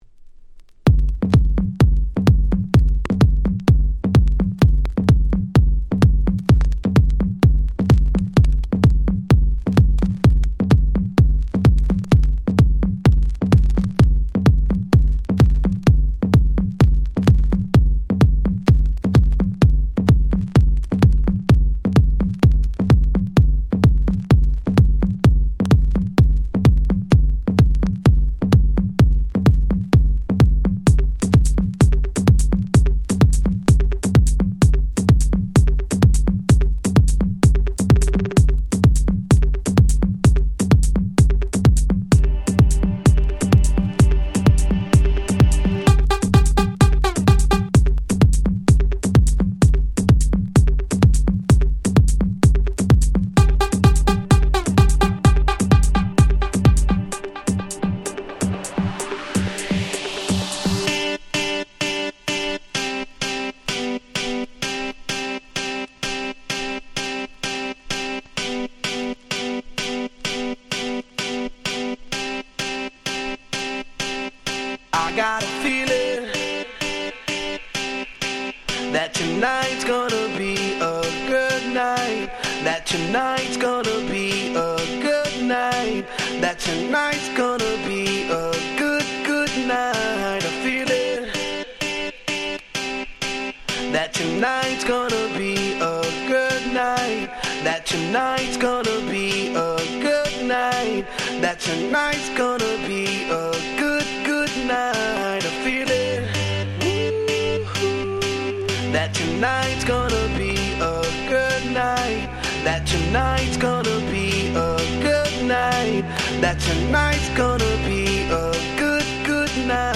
みんな大好き、超絶アゲアゲクラシック！
EDM アゲアゲ パリピ キャッチー系